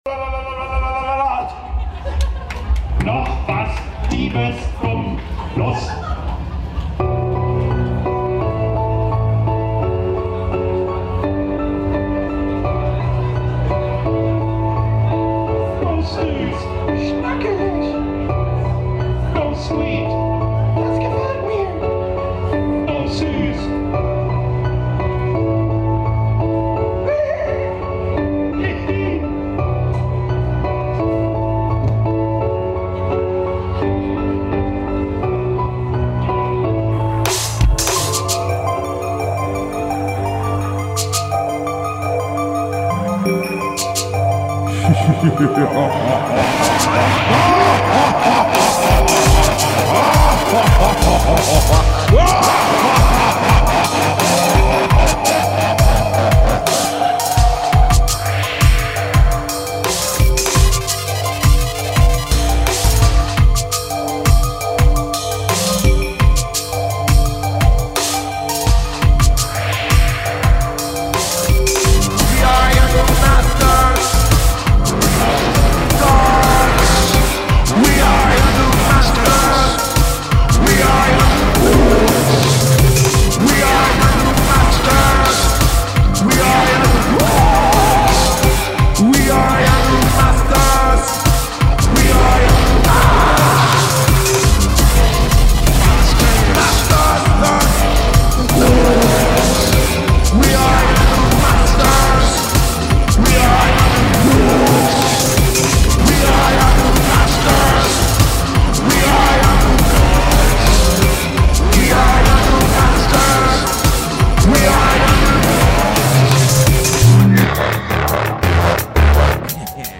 New Punk Music